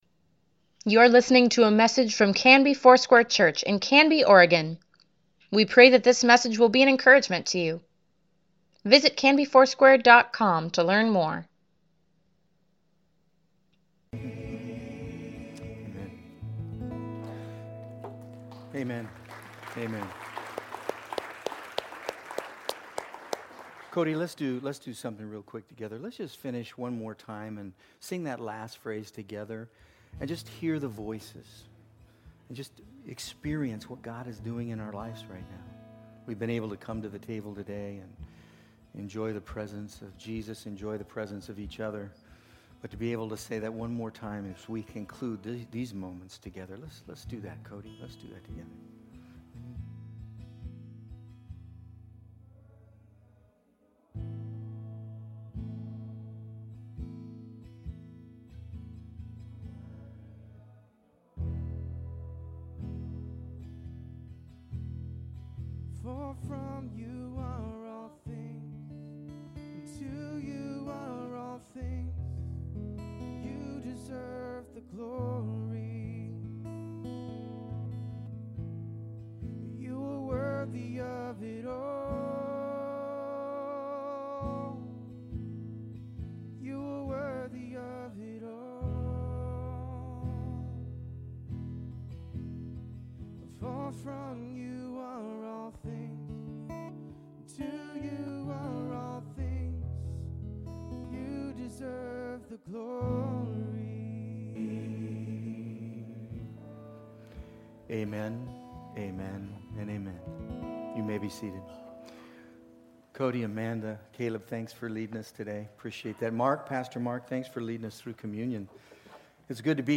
Weekly Email Water Baptism Prayer Events Sermons Give Care for Carus Nehemiah - Introduction February 2, 2020